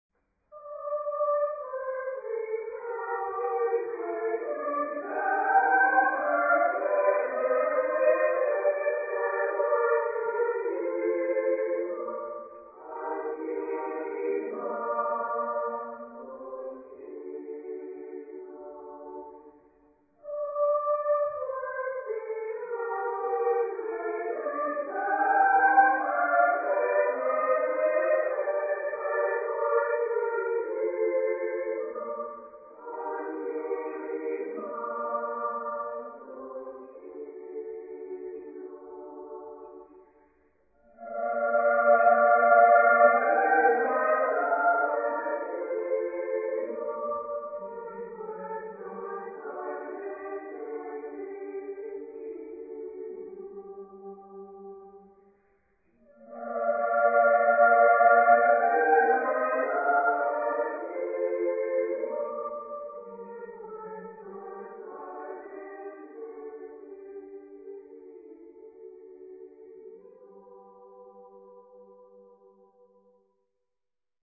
Genre-Style-Form: Canzonetta ; Baroque ; Children ; Secular
Type of Choir: SAA OR SSA  (3 women voices )